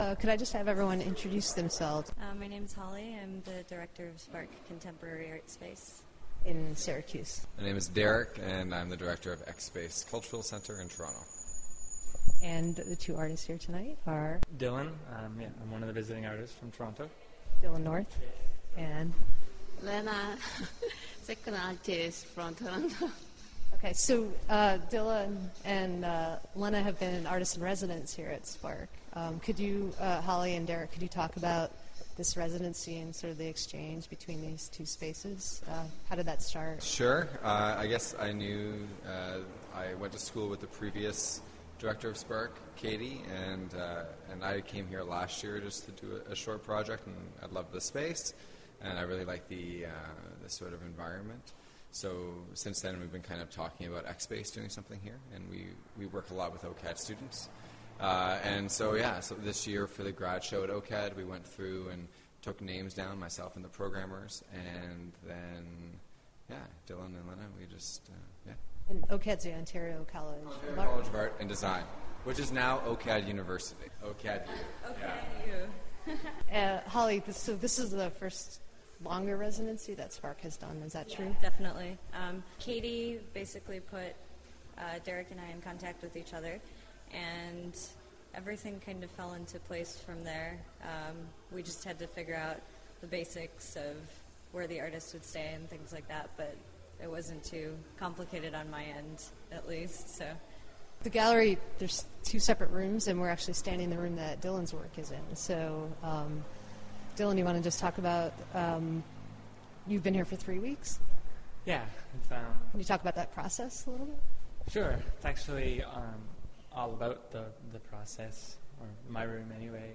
ASS Radio: Interview